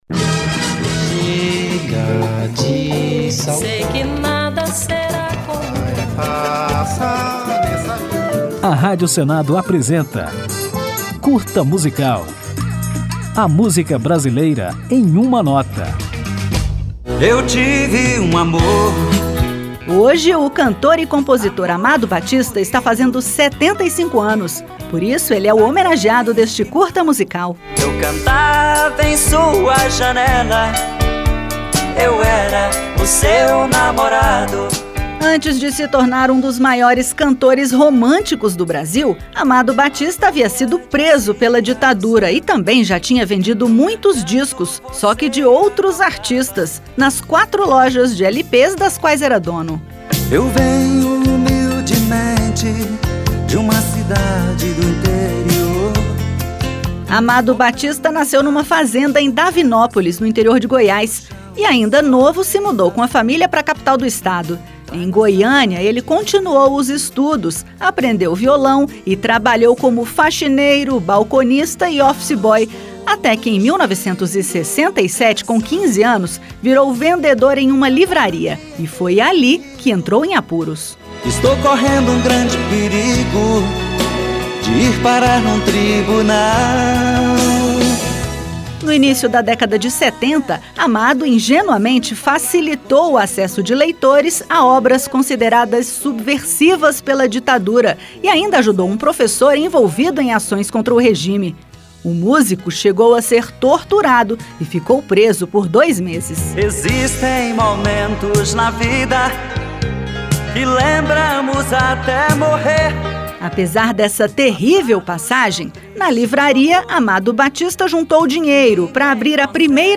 Na nossa homenagem você vai conferir a história do músico que, antes de vender muitos discos como um dos maiores cantores românticos do Brasil, havia sido preso pela ditadura militar e já havia vendido muitos discos, só que de outros artistas, nas quatro lojas de LP das quais era dono em Goiânia. Aperte o play para conferir essas curiosidades, a história do artista e também um dos grandes sucessos de Amado Batista, Meu Ex-Amor, lançado em 1989.